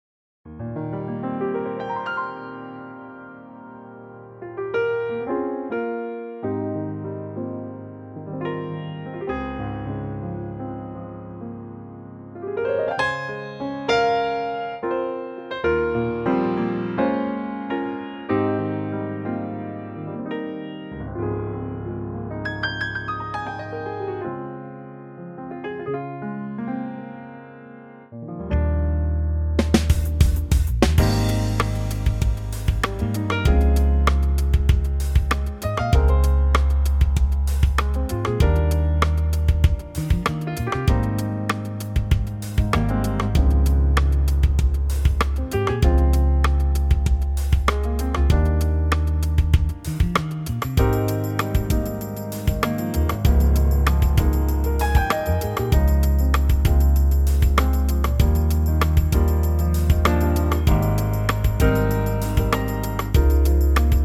Unique Backing Tracks
key - Eb - vocal range - Eb to F ( optional Ab top note)